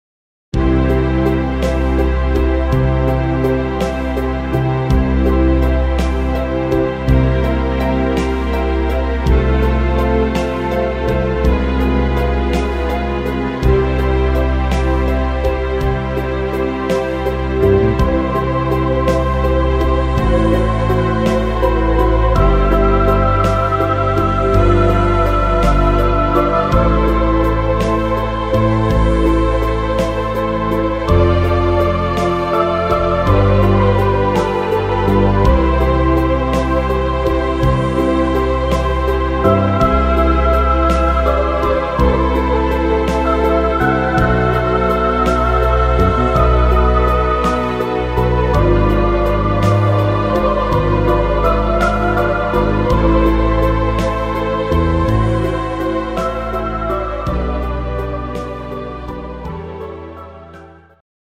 instr. Orgel